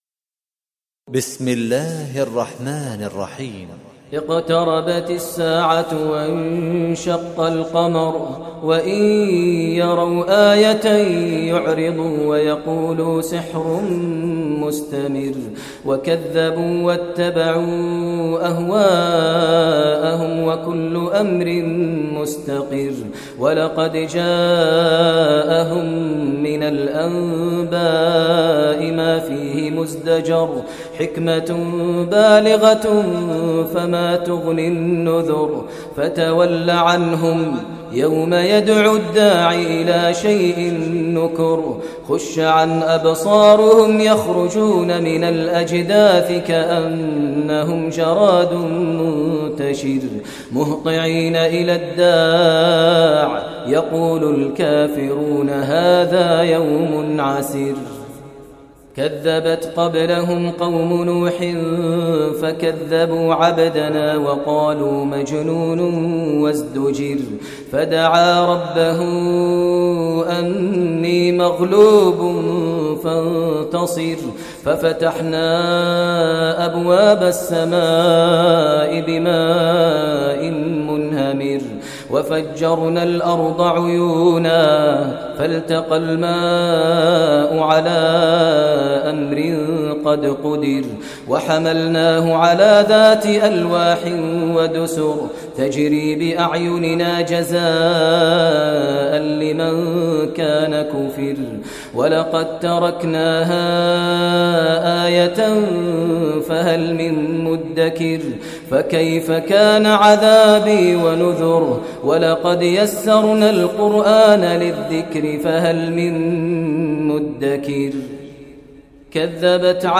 Surah Qamar Recitation by Sheikh Maher Mueaqly
Surah Qamar, listen online mp3 tilawat / recitation in the voice of Sheikh Maher al Mueaqly.